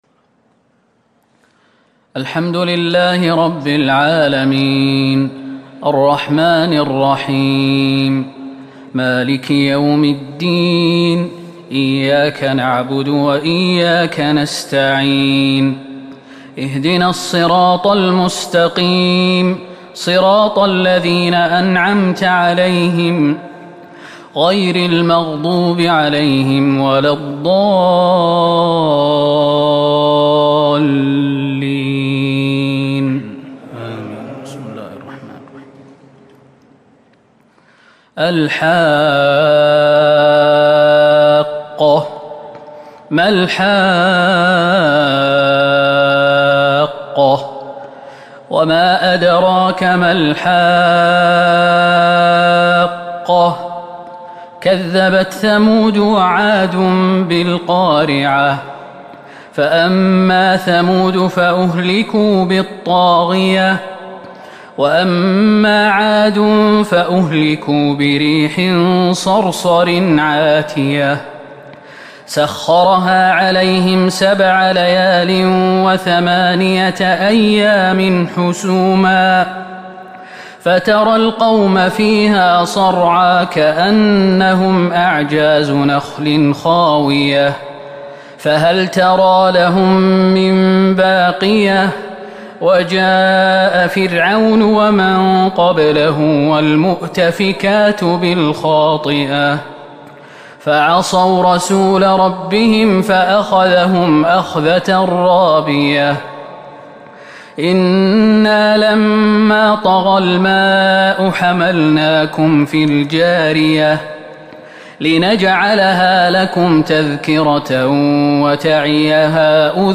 صلاة التراويح l ليلة 29 رمضان 1442 | من سورة الحاقة إلى سورة المدثر | taraweeh prayer The 29th night of Ramadan 1442H > تراويح الحرم النبوي عام 1442 🕌 > التراويح - تلاوات الحرمين